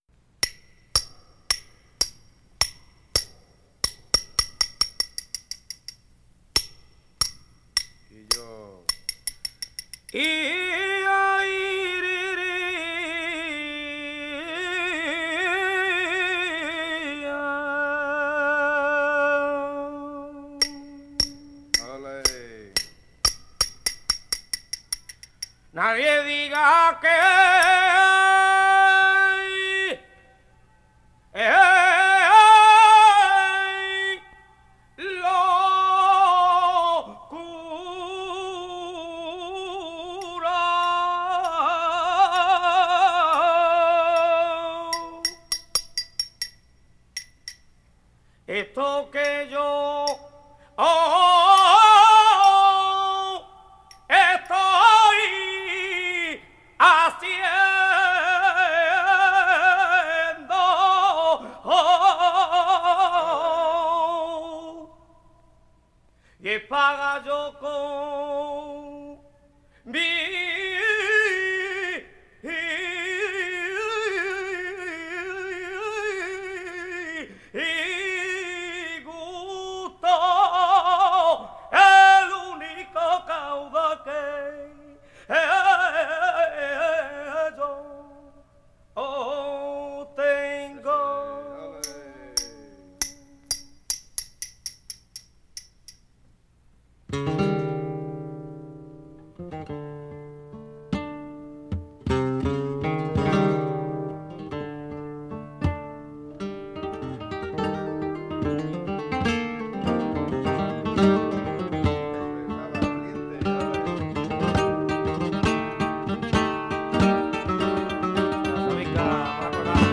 (siguiriyas)